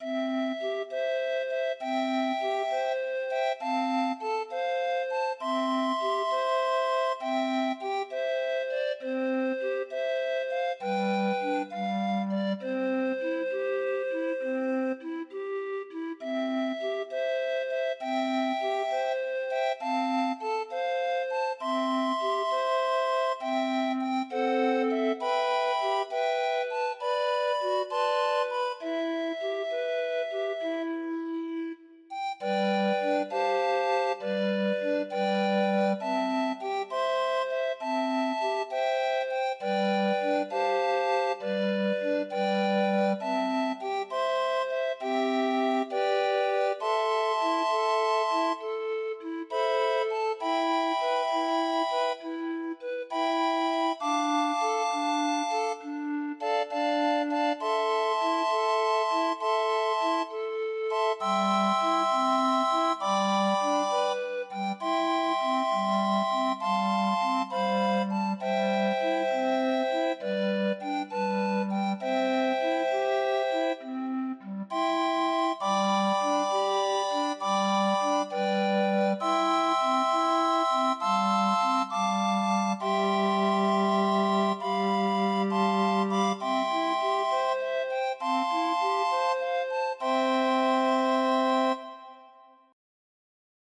Demo of 25 note MIDI file